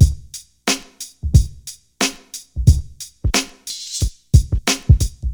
90 Bpm Drum Loop G Key.wav
Free breakbeat sample - kick tuned to the G note. Loudest frequency: 1573Hz
90-bpm-drum-loop-g-key-z5T.ogg